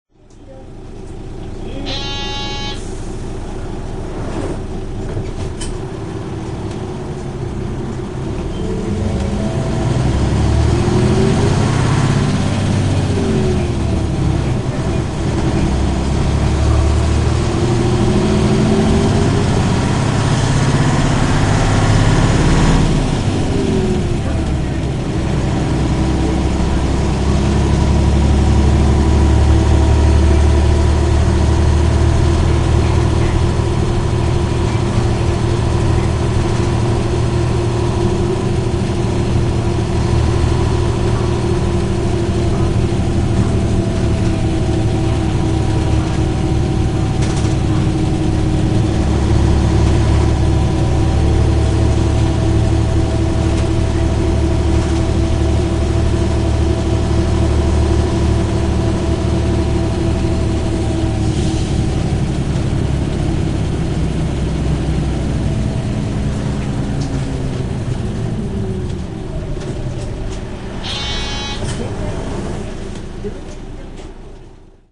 製造メーカー・形式 いすゞ P-LV214M
走行音 [
苦竹一丁目→宮城野区役所前
川内時代の走行音です。